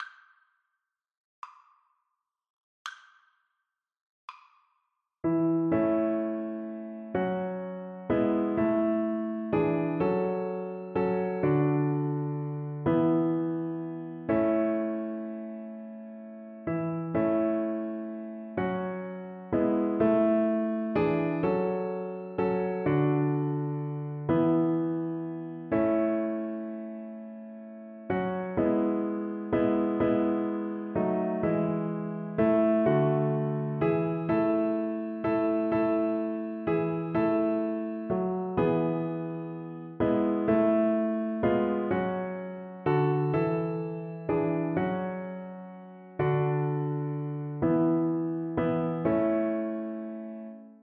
Violin
6/8 (View more 6/8 Music)
A major (Sounding Pitch) (View more A major Music for Violin )
Christmas (View more Christmas Violin Music)